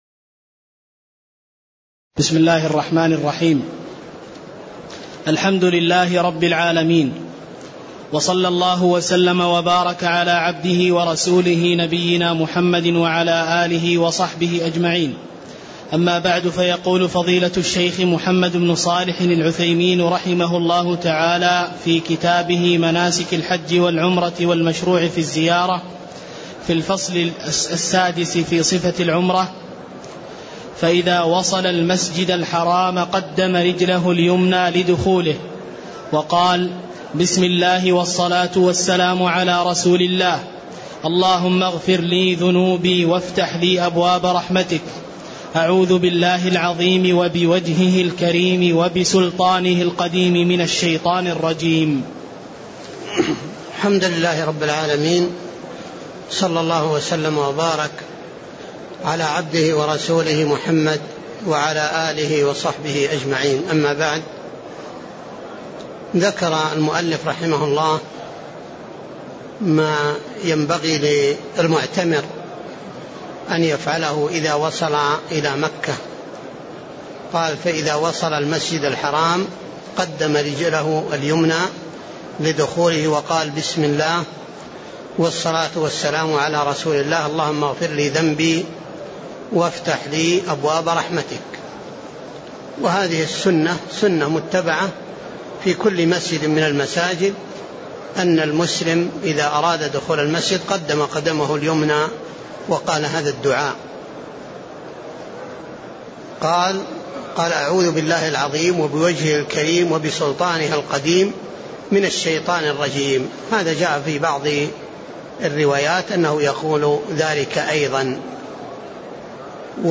الدروس العلمية بالمسجد الحرام والمسجد النبوي